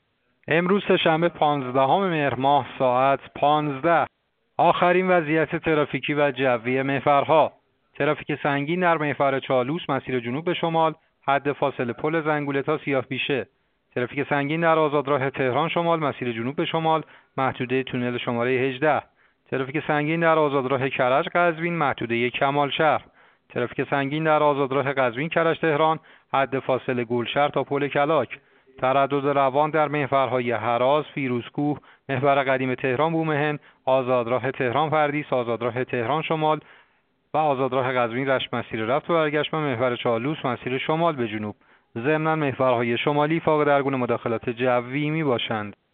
گزارش رادیو اینترنتی از آخرین وضعیت ترافیکی جاده‌ها ساعت ۱۵ پانزدهم مهر؛